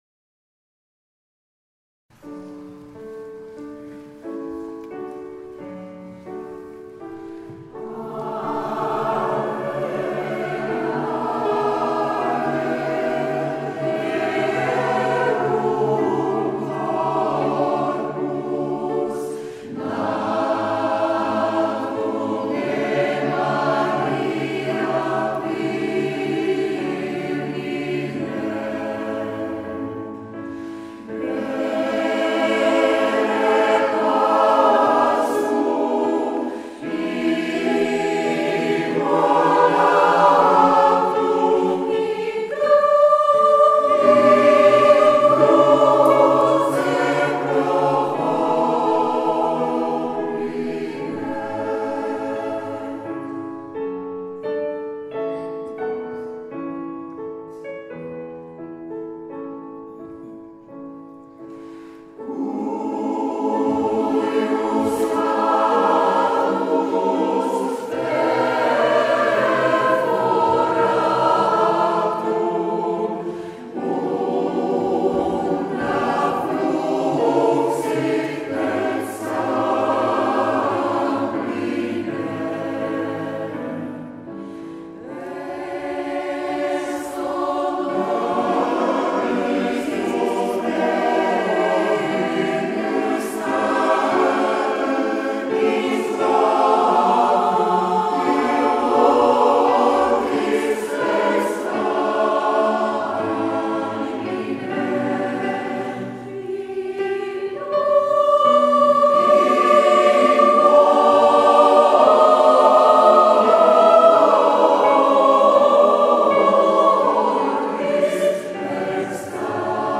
Zvuková ukázka z vystoupení v kostele Panny Marie v Železné Rudě
společné vystoupení obou sborů